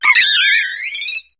Cri d'Étourvol dans Pokémon HOME.